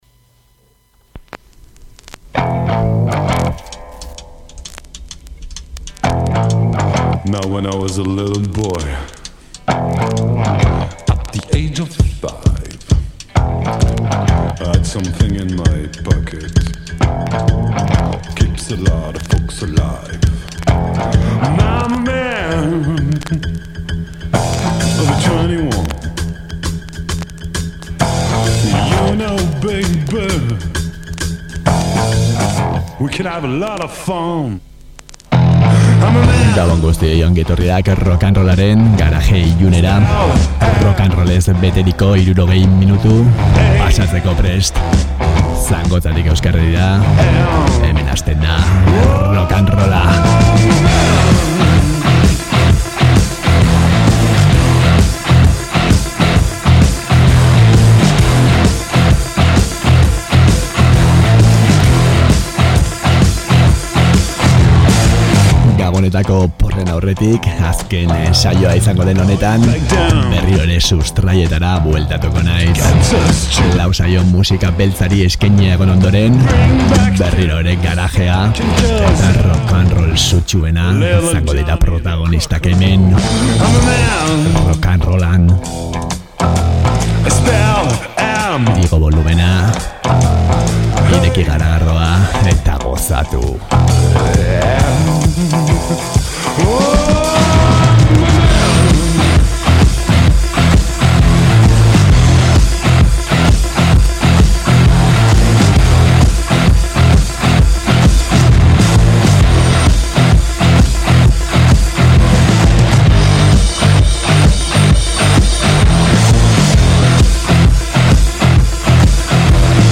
neska hirukote suediarra
punk rock itsaskorra
kitarra riff probokatzaileak
garage ukitua
beat eta psicodelia